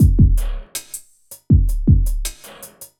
Ambient / Loop / DRUMLOOP093_AMBNT_160_X_SC3.wav